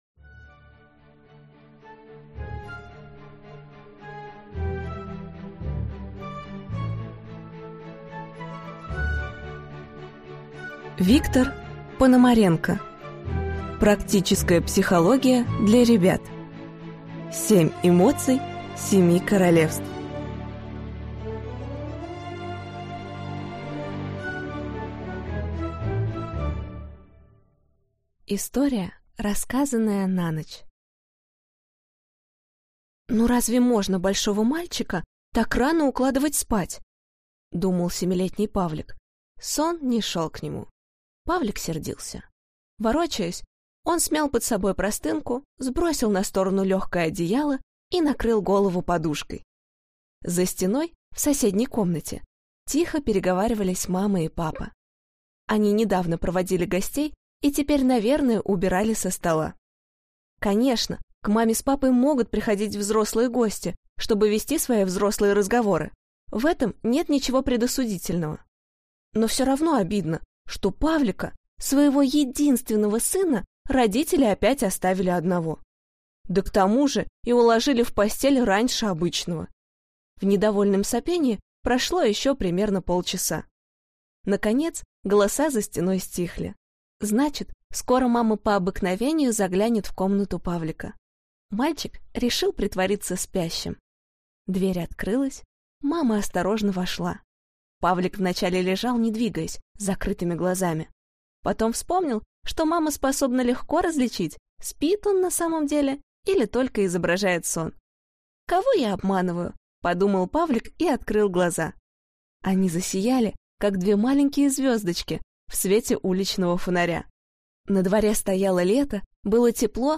Аудиокнига Практическая психология для ребят. Семь эмоций семи королевств | Библиотека аудиокниг
Прослушать и бесплатно скачать фрагмент аудиокниги